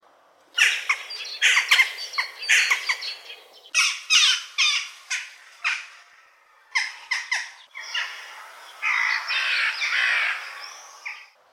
На этой странице собраны звуки галки – от характерных криков до пересвистов с сородичами.
Голоса птицы галки